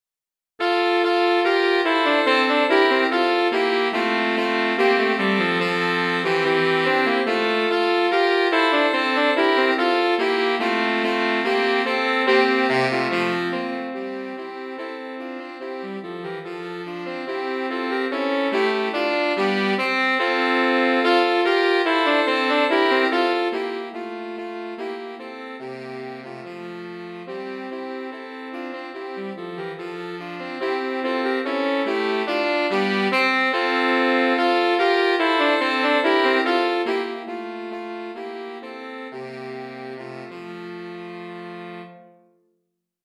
3 Saxophones